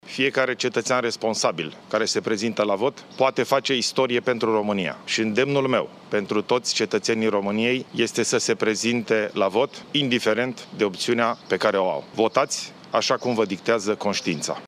Președintele interimar, Ilie Bolojan, i-a îndemnat pe români să meargă la vot.